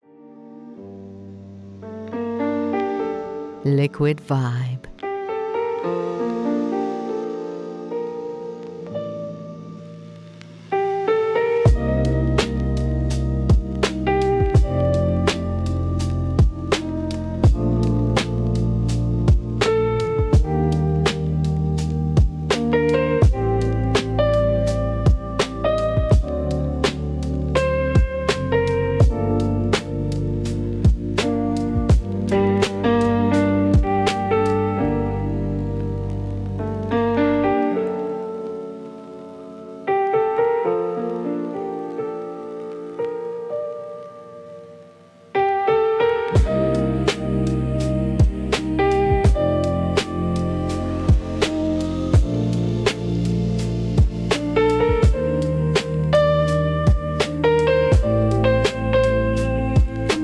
Enjoy a 1-Minute Sample – Purchase to Hear the Whole Track